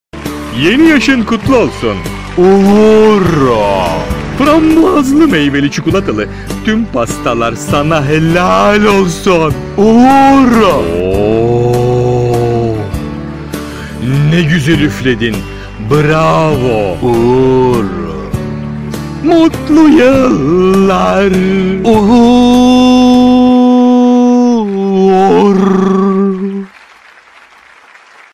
komik
şarkısını